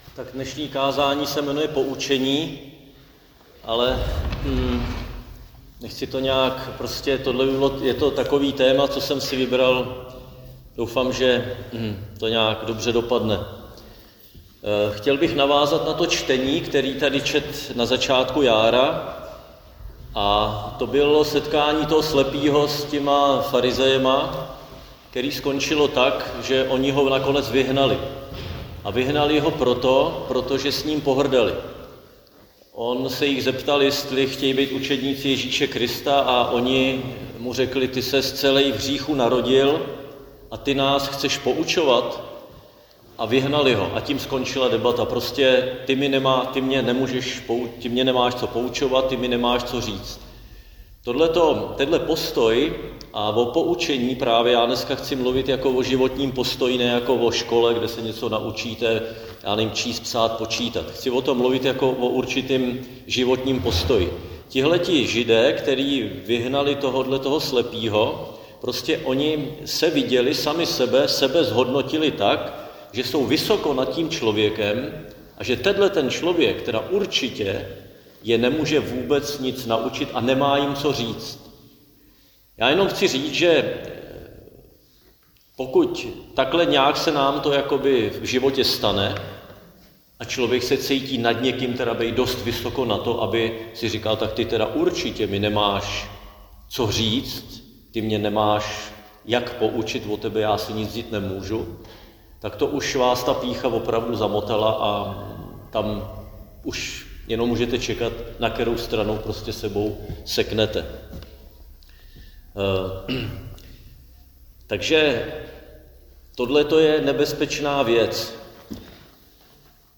Křesťanské společenství Jičín - Kázání 15.3.2026